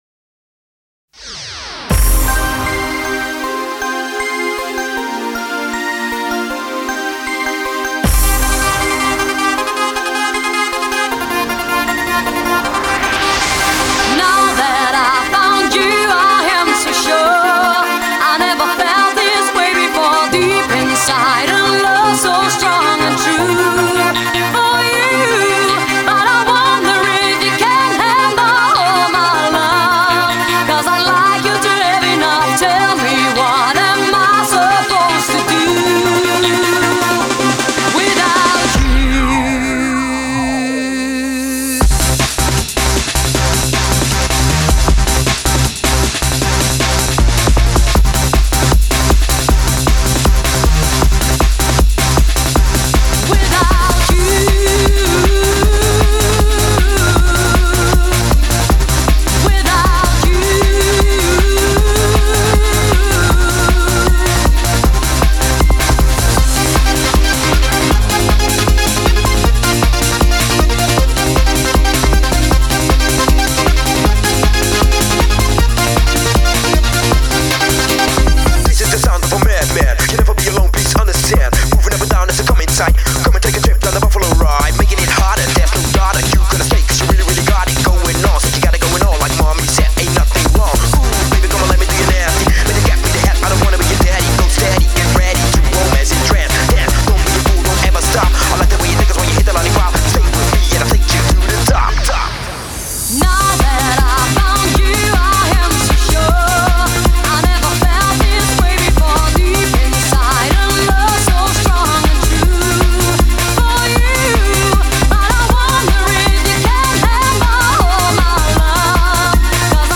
DISKOTEKA_EURODANCE_promodj_.mp3